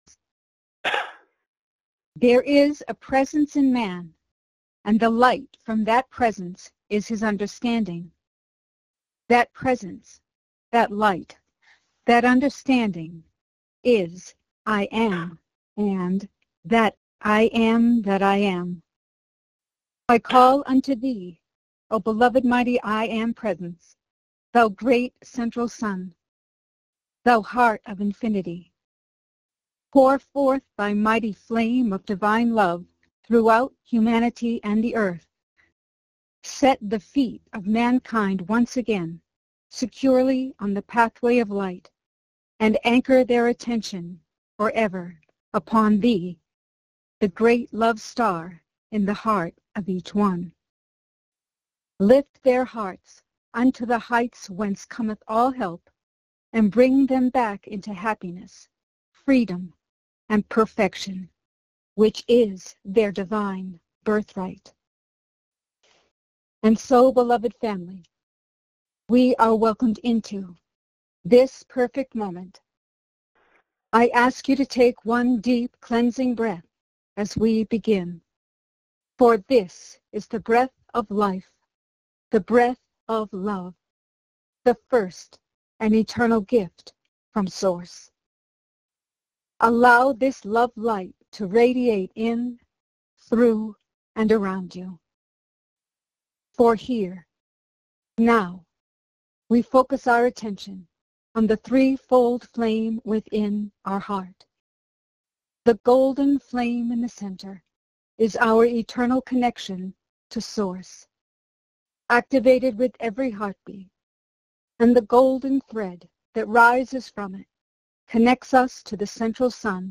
Join Lord Sananda in group meditation.